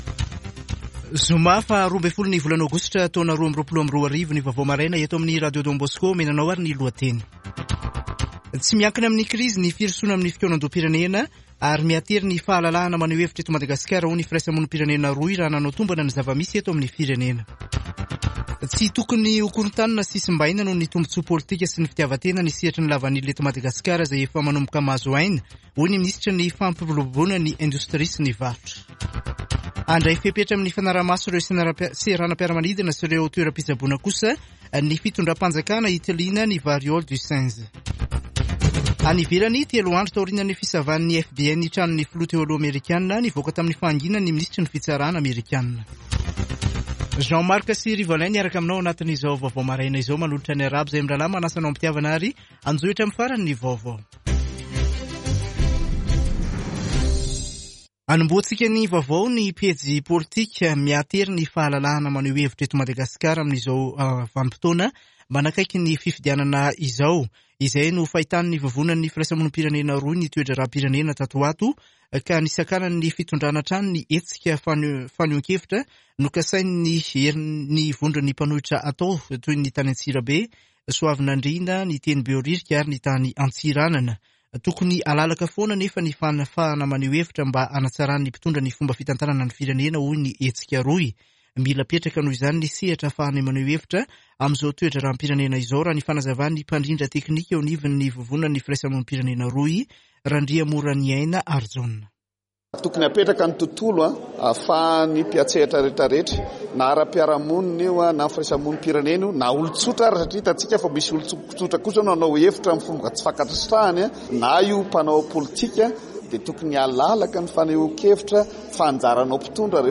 [Vaovao maraina] Zoma 12 aogositra 2022